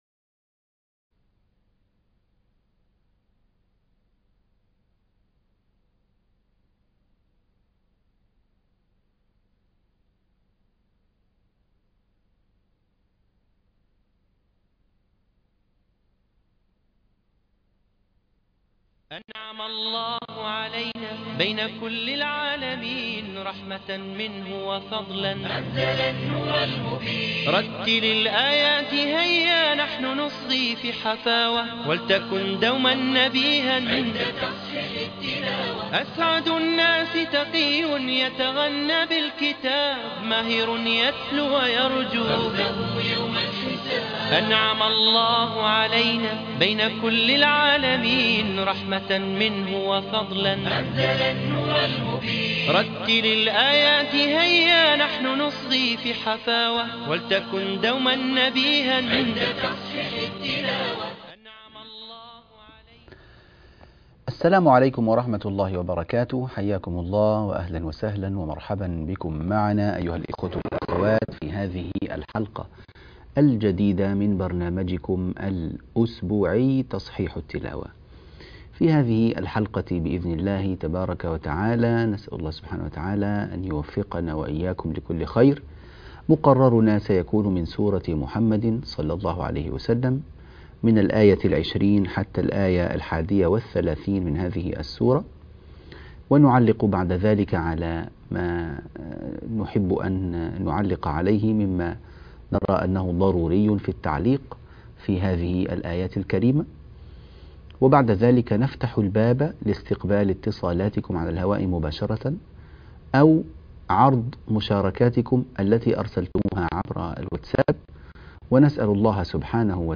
سورة محمد الآيات 20 - 31 - تصحيح التلاوة